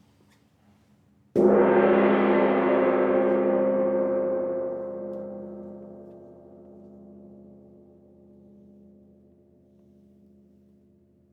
petit_1coup_faible.wav